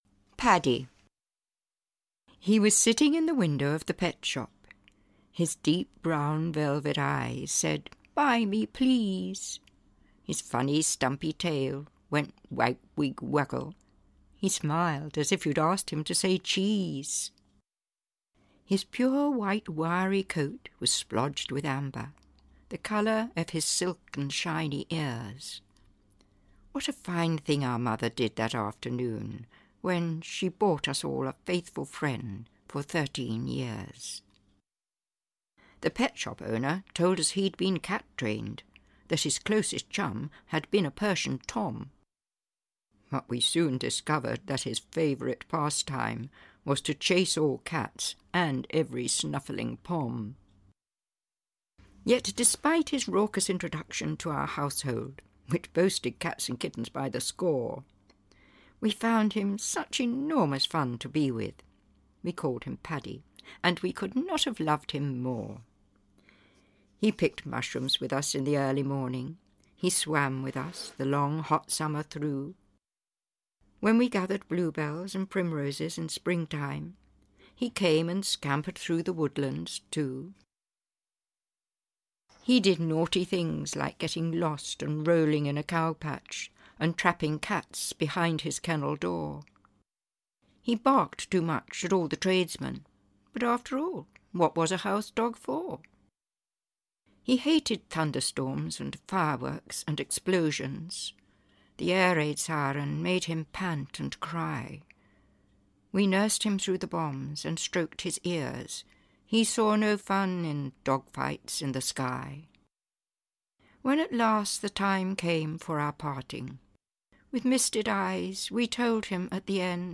Animals In My Life (EN) audiokniha
Ukázka z knihy